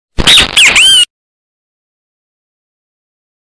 Áudio do Bem-Te-Vi Estourado
Categoria: Sons de memes
audio-do-bem-te-vi-estourado-pt-www_tiengdong_com.mp3